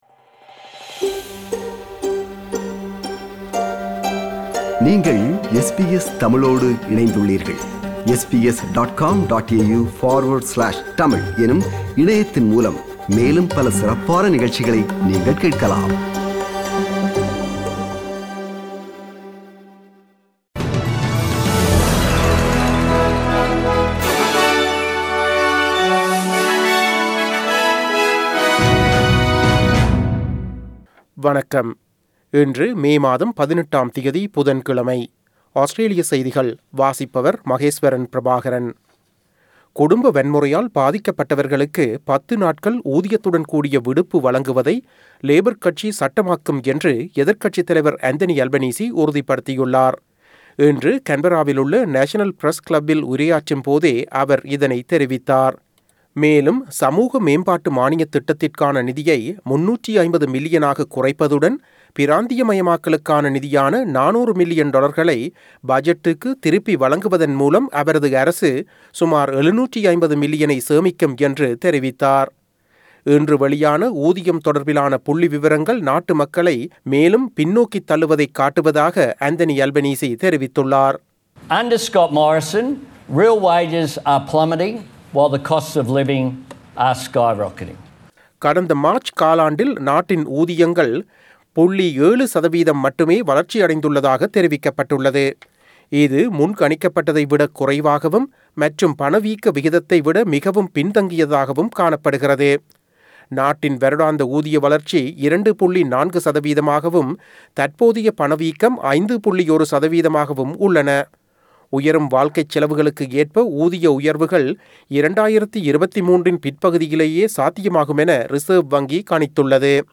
Australian news bulletin for Wednesday 18 May 2022.